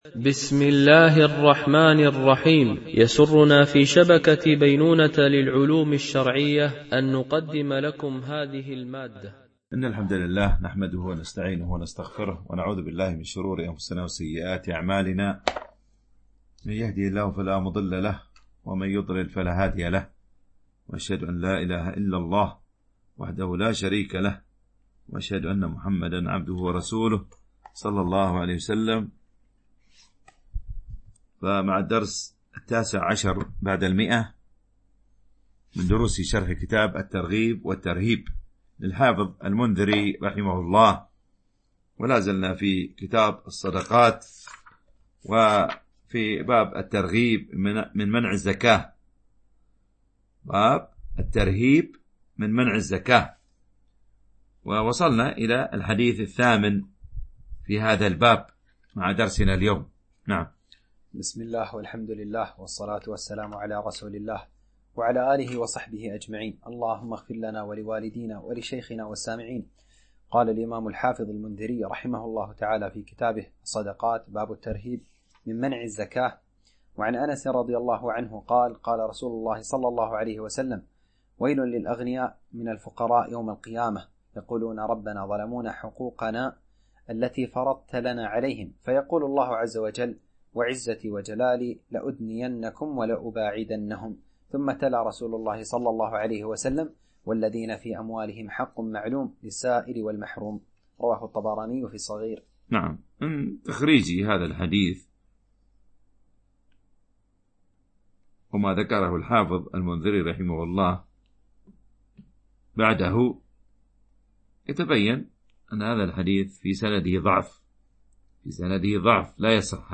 شرح كتاب الترغيب والترهيب - الدرس 119 ( كتاب الصدقات - باب الترهيب من منع الزكاة ... - الحديث 1137 - 1154 )